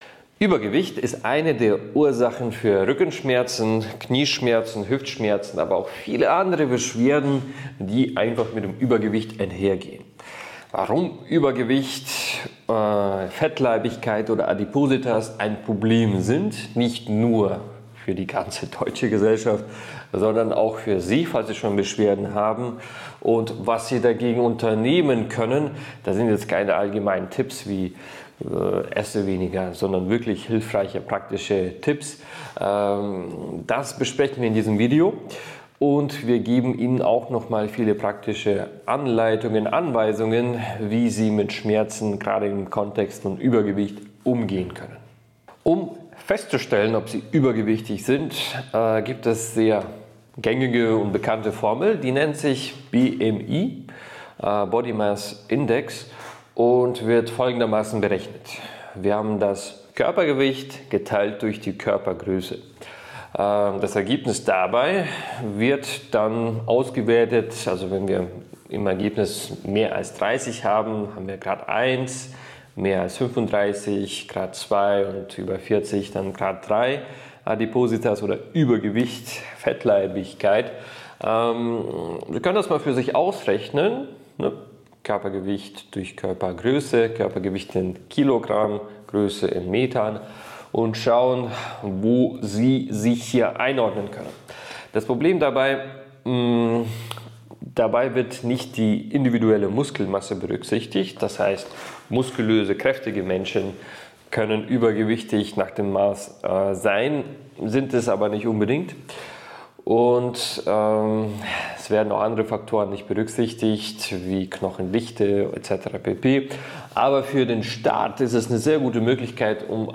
In diesem Gespräch wird die Problematik des Übergewichts und dessen Auswirkungen auf die Gesundheit umfassend behandelt. Es werden die Ursachen von Übergewicht, die Rolle der Ernährung und Bewegung sowie psychische Aspekte thematisiert. Praktische Tipps zur Gewichtsreduktion und zur Verbesserung der allgemeinen Gesundheit werden gegeben, wobei der Fokus auf der Notwendigkeit von Bewegung und einer ausgewogenen Ernährung liegt.